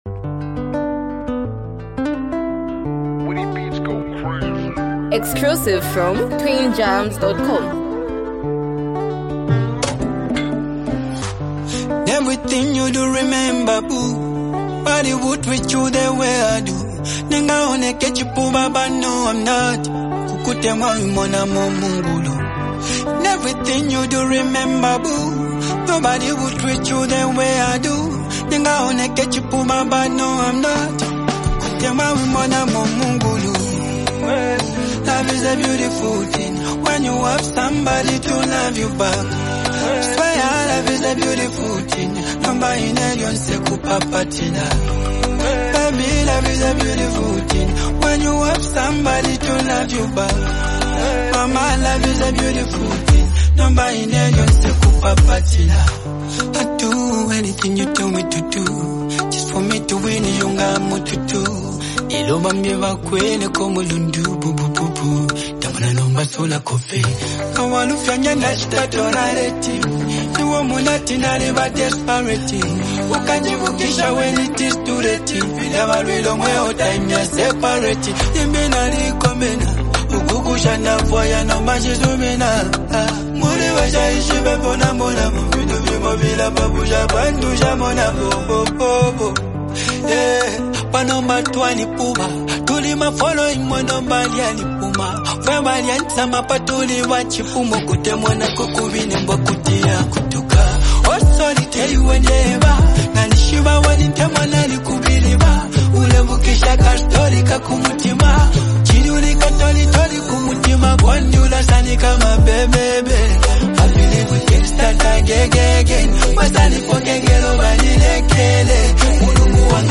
soulful and uplifting song